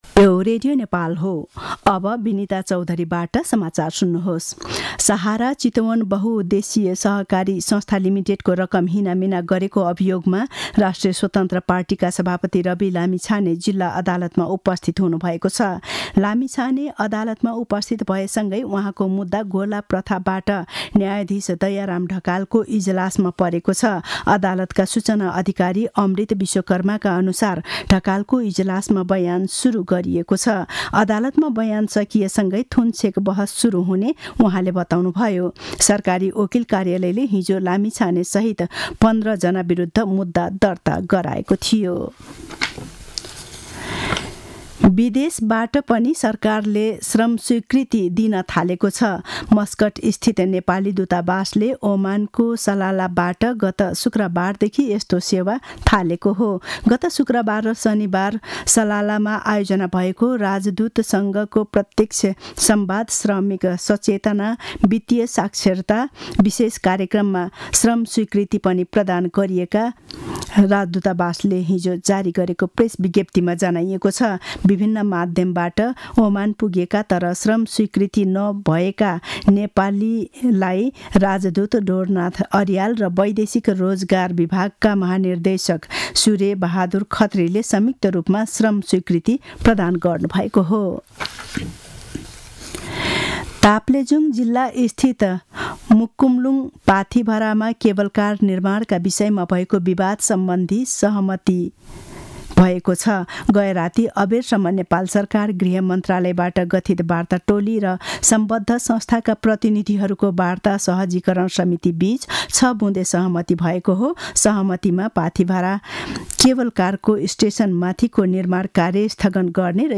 दिउँसो १ बजेको नेपाली समाचार : २४ माघ , २०८१
1-pm-news-1.mp3